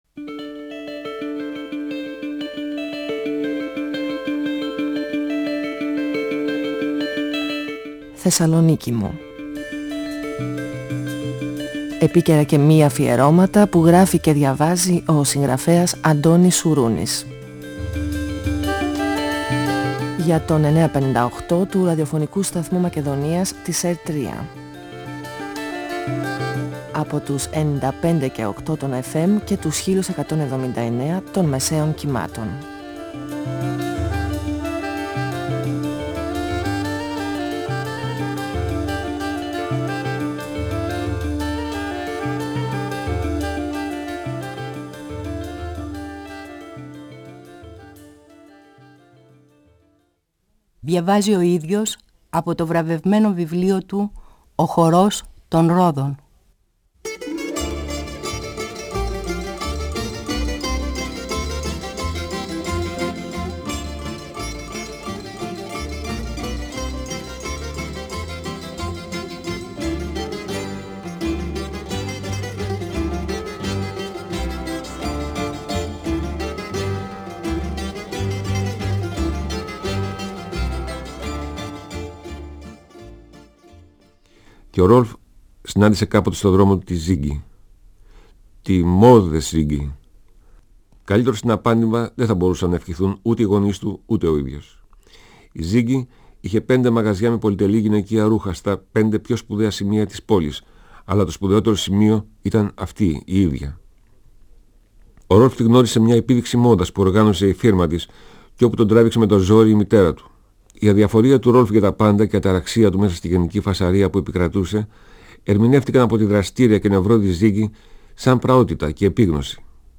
Ο συγγραφέας Αντώνης Σουρούνης (1942-2016) διαβάζει το πρώτο κεφάλαιο από το βιβλίο του «Ο χορός των ρόδων», εκδ. Καστανιώτη, 1994. Ο Ρολφ γνωρίζει και παντρεύεται τη Ζίγκρι Όφενμπαχ.